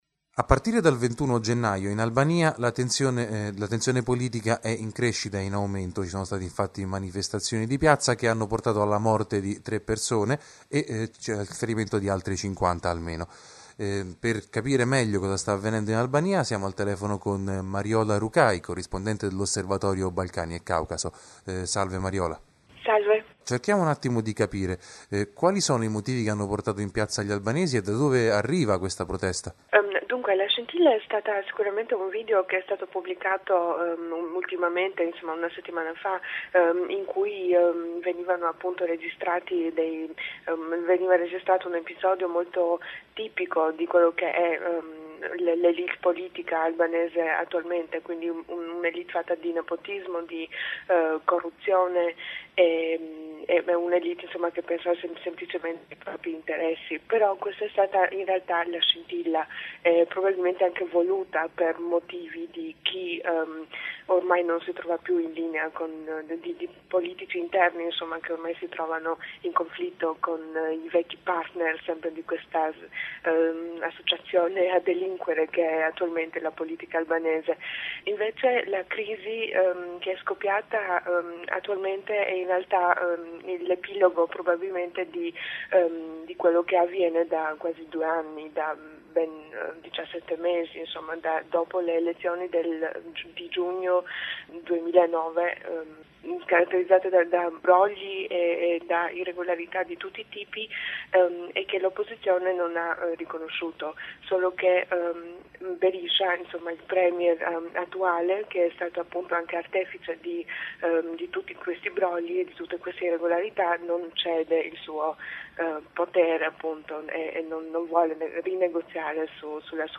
Le strade di Tirana sembrano essere tornane alla normalità dopo le manifestazioni dei giorni scorsi. Il discusso premier Berisha parla di tentato golpe, ipotesi smentita dalla stampa indipendente che racconta come la guardia nazionale abbia lasciato sguarniti alcun edifici istituzionali nell’apparente tentativo di tendere una trappola all’opposizione. Un’intervista